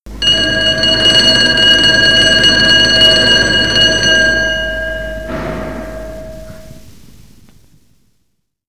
School Bell – 1960
school-bell-jh.mp3